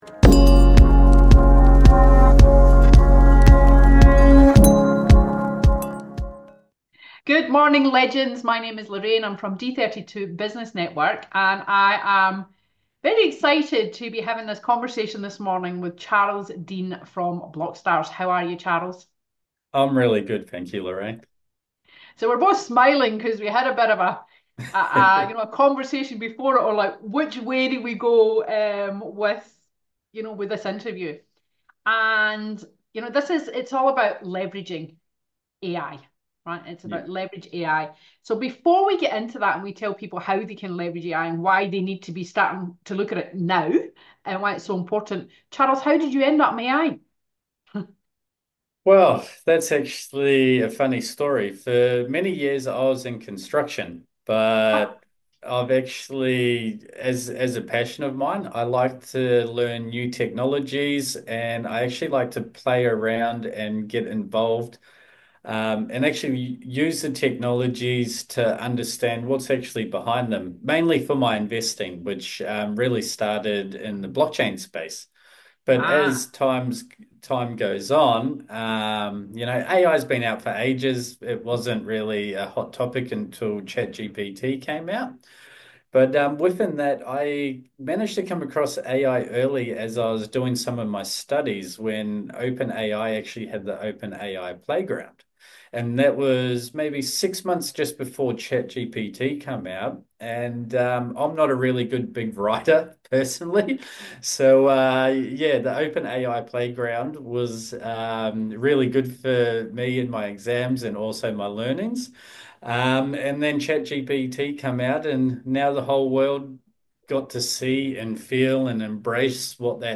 From global investments in artificial intelligence to real-life use cases like video AI, this conversation is packed with valuable insights you don’t want to miss.